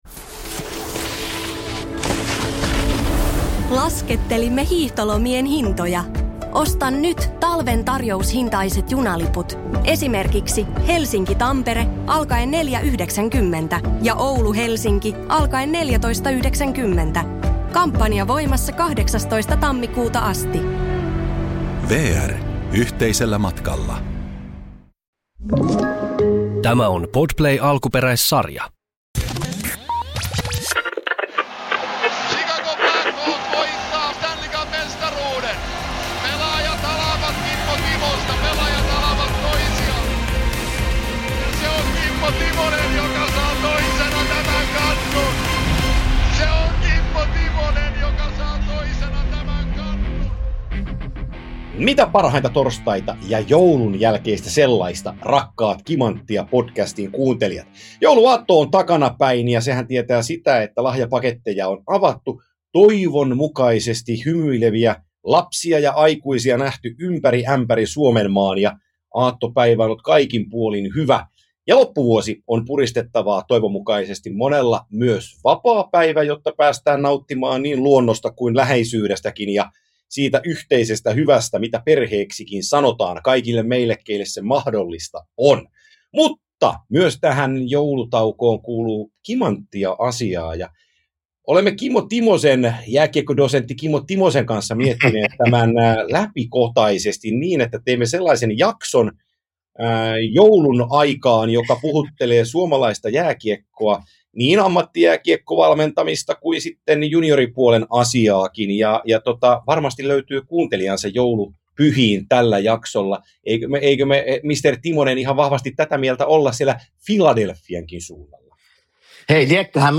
Loppuvuoden kunniaksi pitkä keskustelu kotimaisen jääkiekkoilijan matkasta kohti kirkkaita valoja.